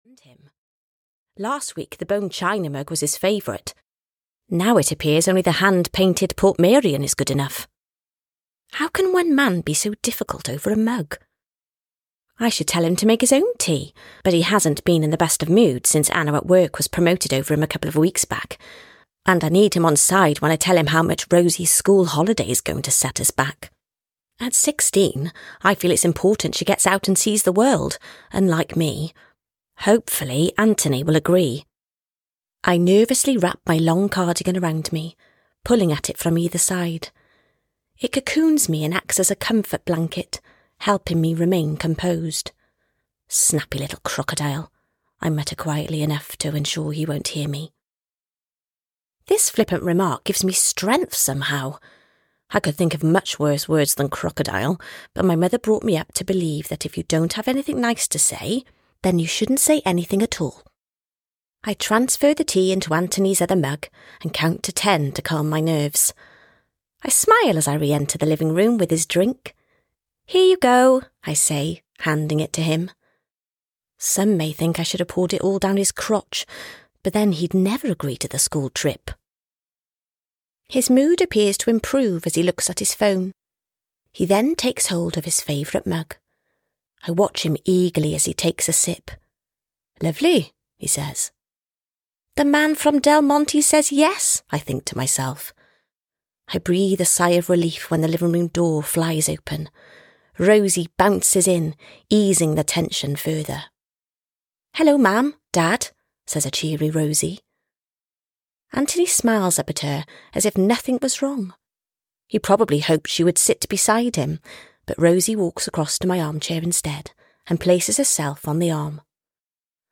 A Scandinavian Summer (EN) audiokniha
Ukázka z knihy